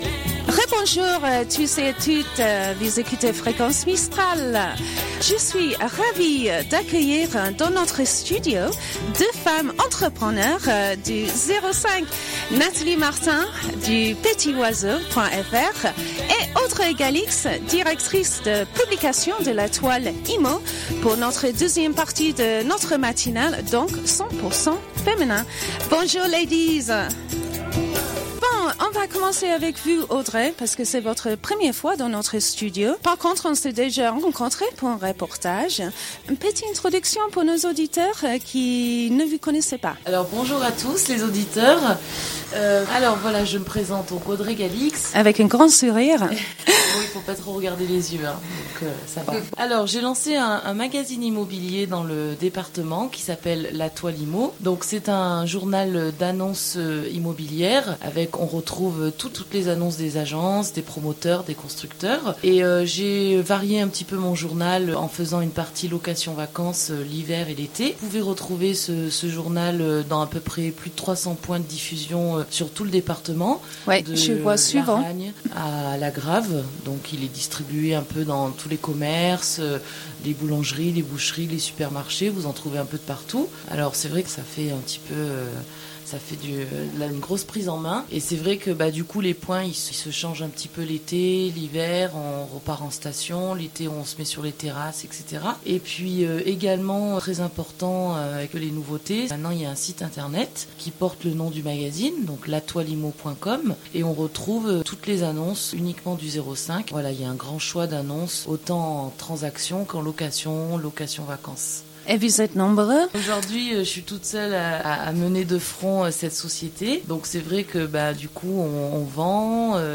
Émission 100% féminine avec deux entrepreneuses des Hautes-Alpes !
Ces deux femmes, ayant eu le courage de lancer leur entreprise, présentent leurs actions et leur vie de chef d'entreprise dans notre studio.